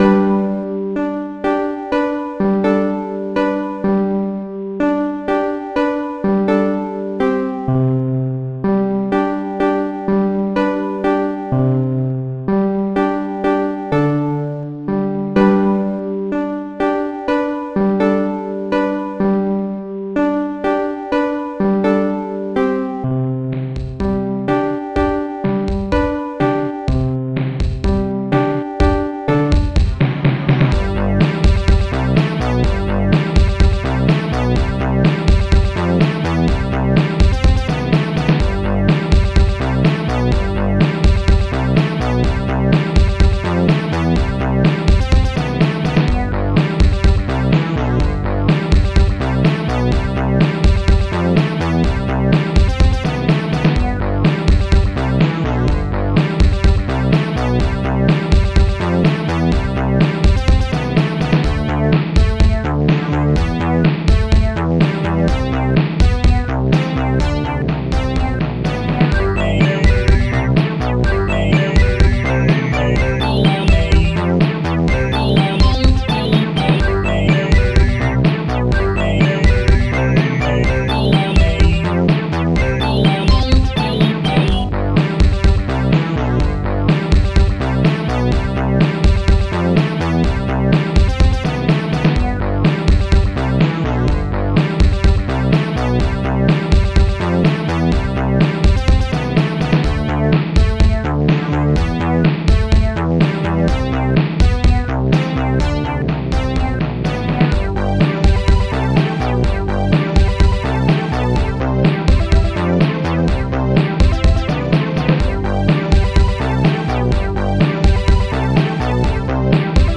Instruments funbass korgbeau bigbow bassdrum3 snare2 hooman strings4 shamus hallbrass organ strings1 conga monsterbass strings2 ringpiano